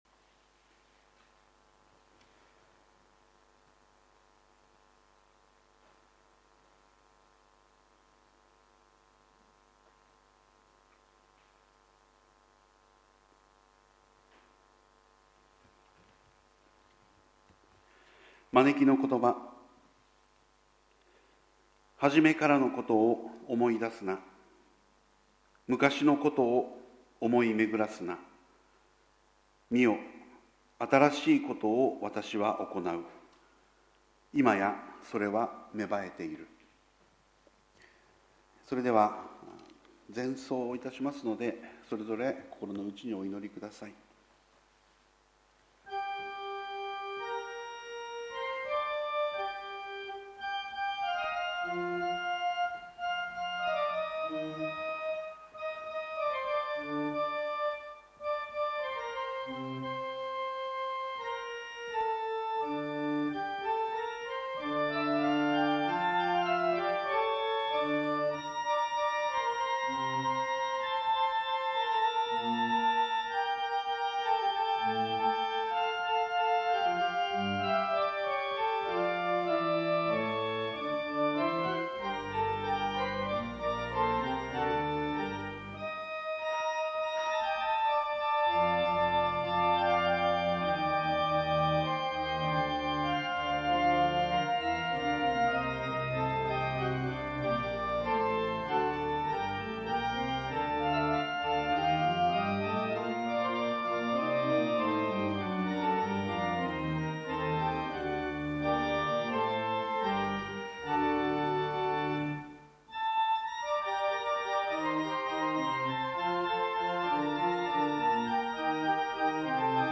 主 日 礼 拝 2020年４月26日
************************************************ ＜ 音声のみ＞ 礼拝全体の録音になっています。 マイク音声のため音質良好！